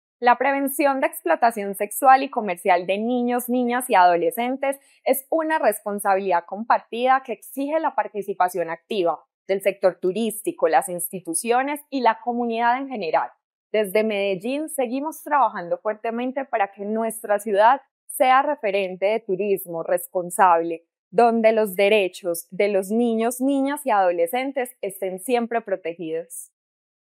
Declaraciones secretaria (e) de Turismo y Entretenimiento, Ana María Mejía
Declaraciones-secretaria-e-de-Turismo-y-Entretenimiento-Ana-Maria-Mejia-1.mp3